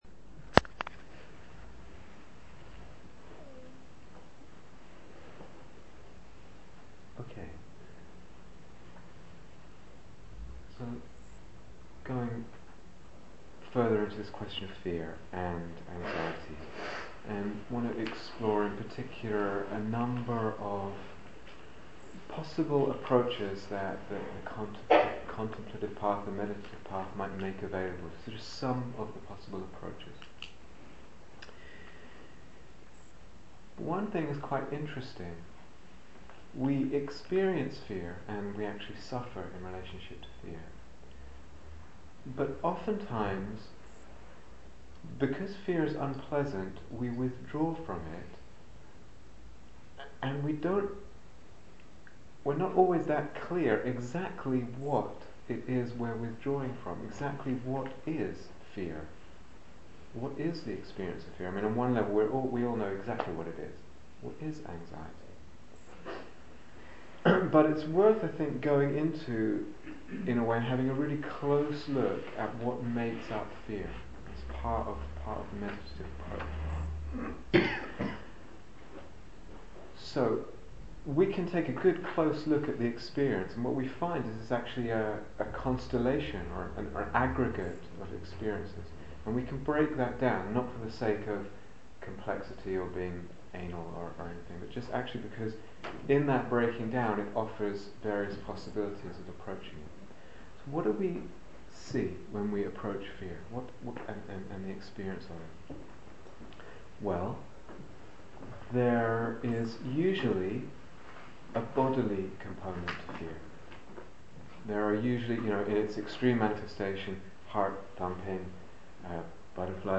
Freedom from Fear and Anxiety (Dharma Talk)